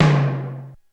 Index of /90_sSampleCDs/300 Drum Machines/Korg DSS-1/Drums03/01
MedTom.wav